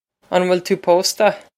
An bhfuil tú pósta? On wil too poh-sta?
On wil too poh-sta?
This is an approximate phonetic pronunciation of the phrase.